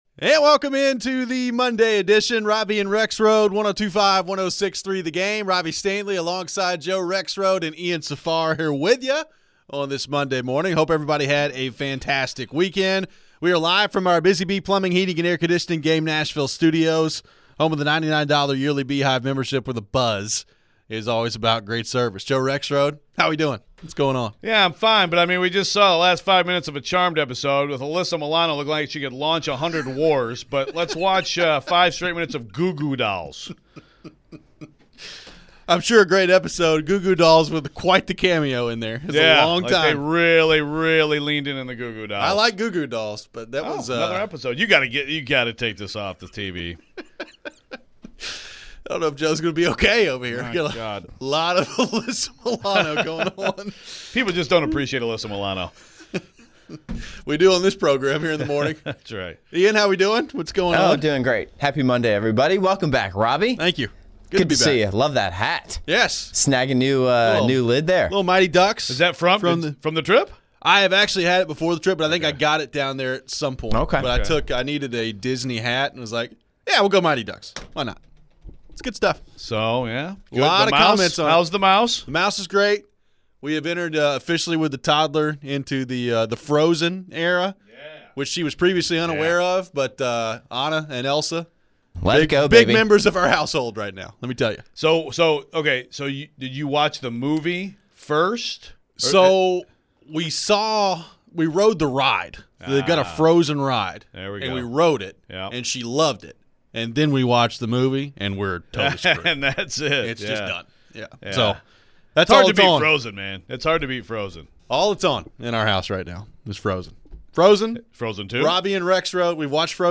Reports are out that WR Trylon Burks suffered a collarbone injury and what does that mean for his future in the NFL? Rookie QB Cam Ward was getting some hype for his performance in the practice. We take your phones.